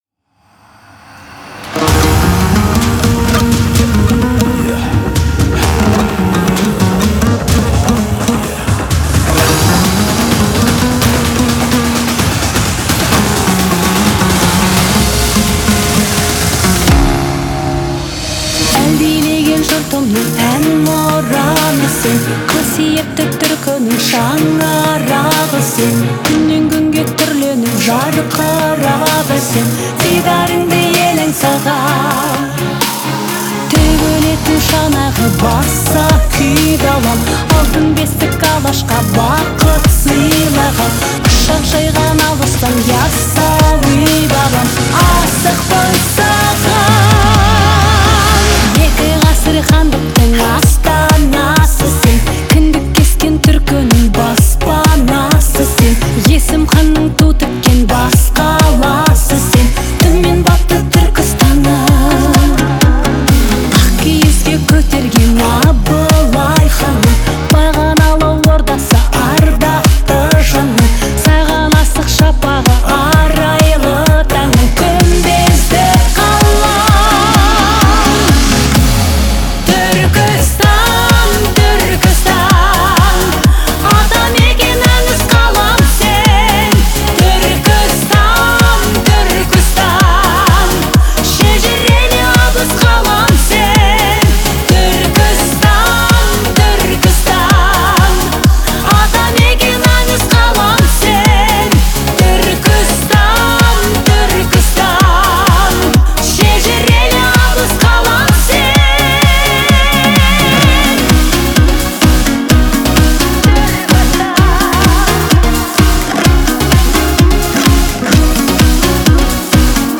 это яркий образец казахской поп-музыки.